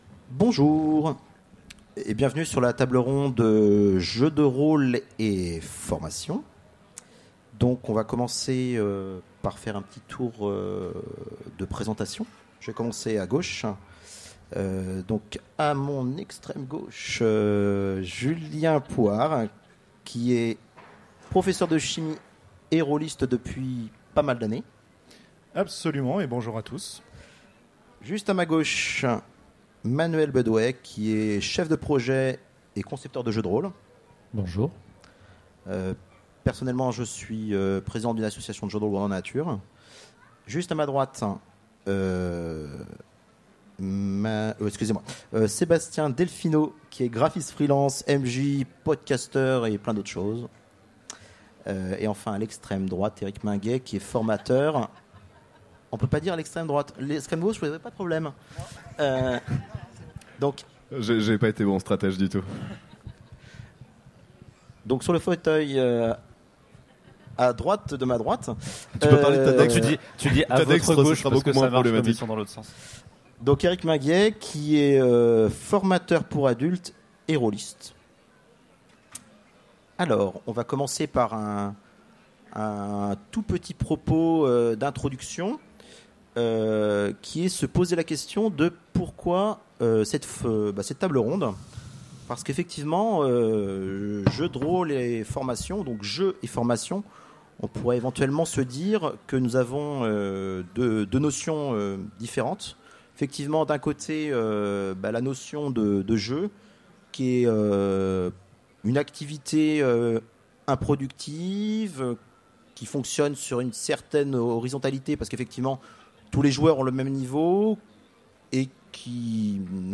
Utopiales 2016 : Conférence Jeu de rôle et formation
Conférence